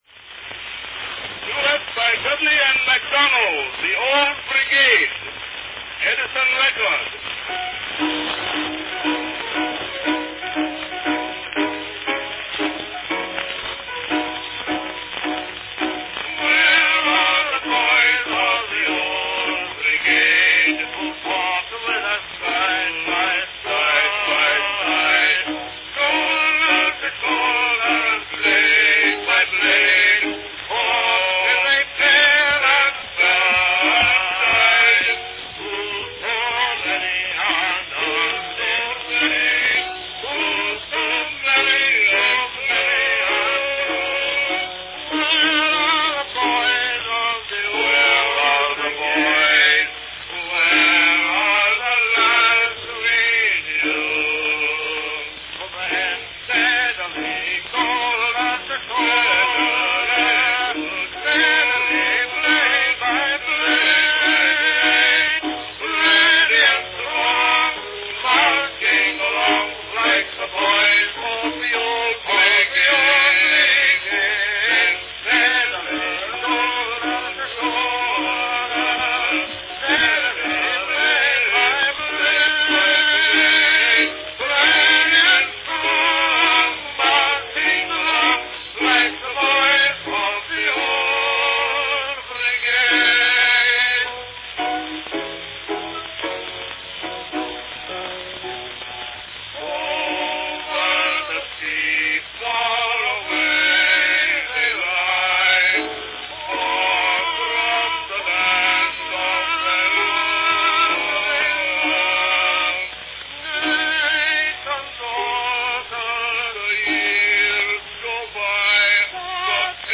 Category Duet
Baritone